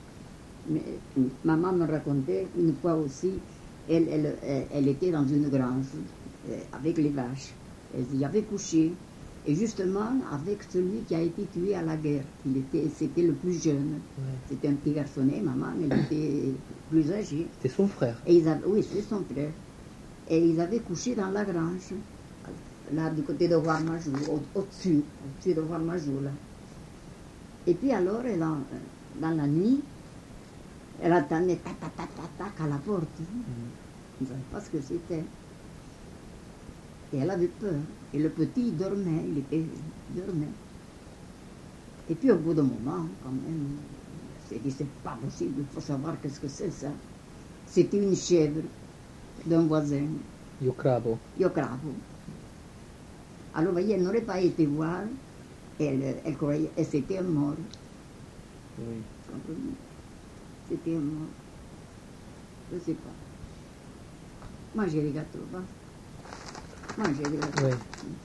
Aire culturelle : Couserans
Genre : conte-légende-récit
Effectif : 1
Type de voix : voix de femme
Production du son : parlé
Classification : récit anecdotique